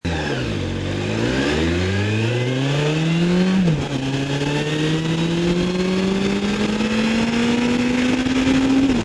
Quelques sons de pots au format mp3: